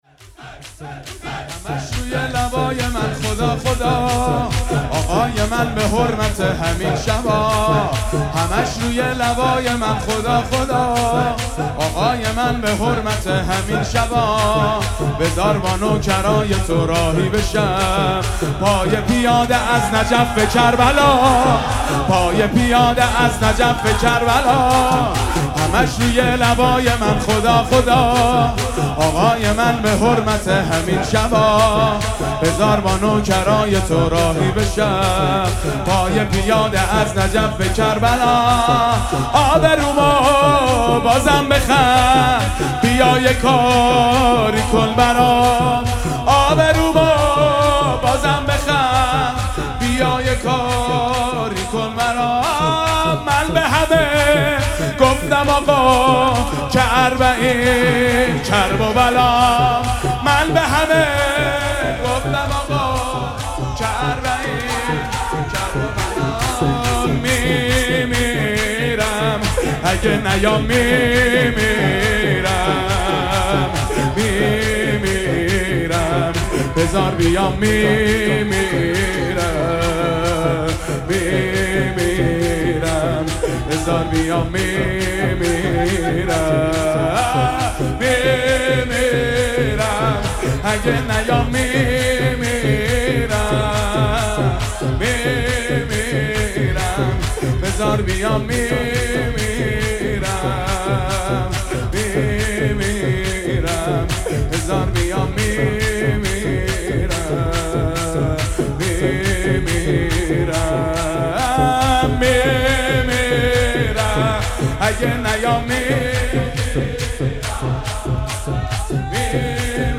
مداح
مناسبت : دهه اول صفر
قالب : شور